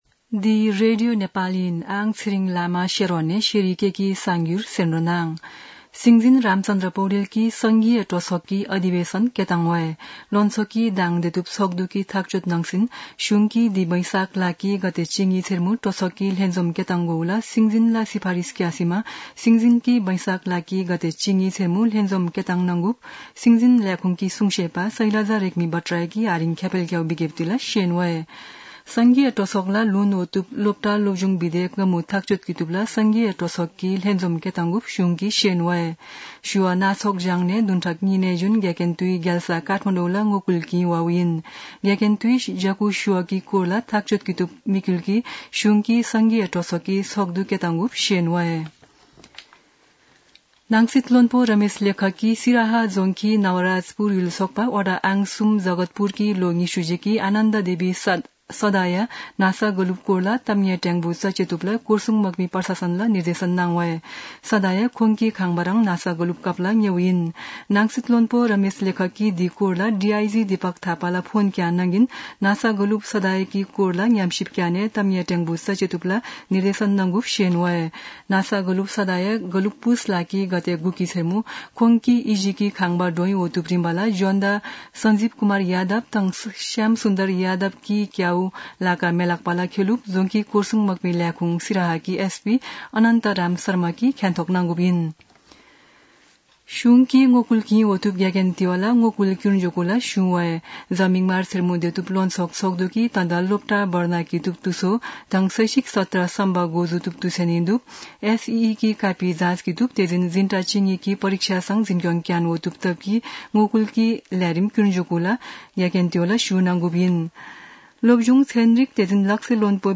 शेर्पा भाषाको समाचार : ३ वैशाख , २०८२
Shearpa-news-.mp3